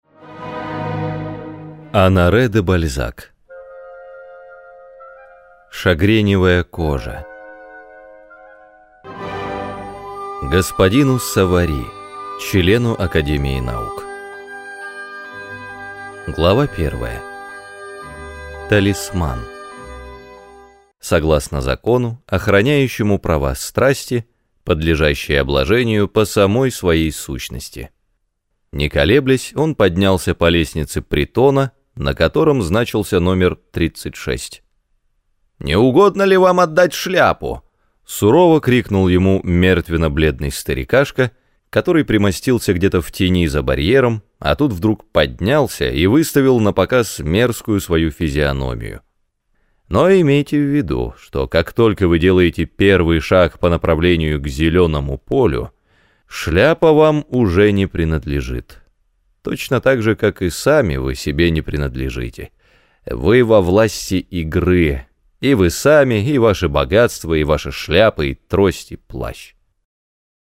Раздел: Аудиокниги
Роман озвучен по заказу издательства "АРДИС"